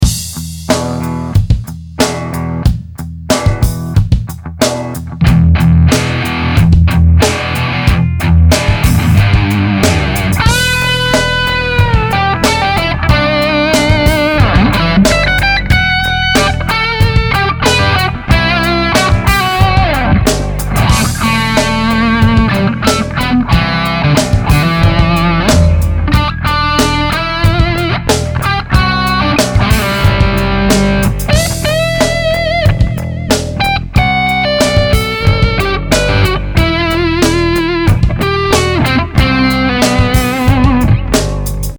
Tube Distorsion Pedal
The Supercharger GTO is an all tube overdrive pedal which incorporates the same circuitry used in our legendary 100w Super Lead Overdrive.
The Supercharger GTO plugs directly into a wall outlet and runs its two 12AX7 tubes at full amp voltages, for a real tube sound.
- Demo> 984 KB In stock ! (and it really delivers, man!)